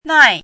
chinese-voice - 汉字语音库
nai4.mp3